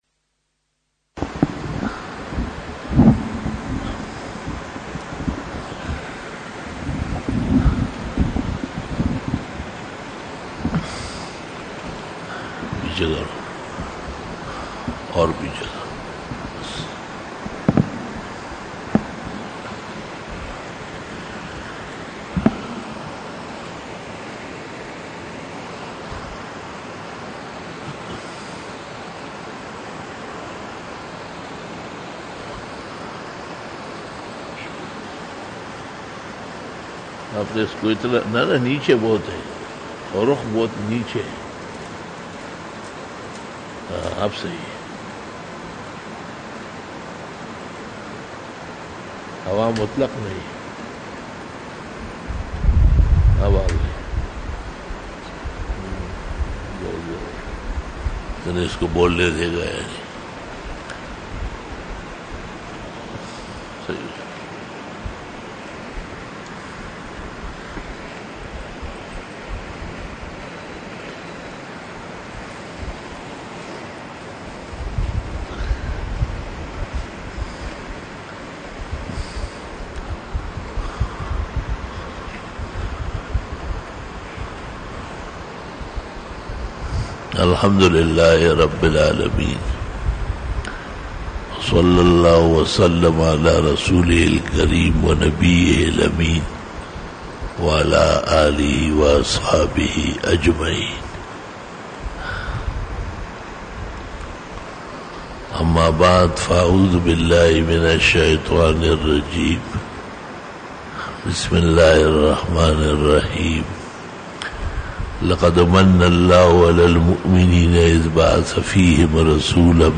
26 BAYAN E JUMA TUL MUBARAK (28 June 2019) (24 Shawwal 1440H)
Khitab-e-Jummah 2019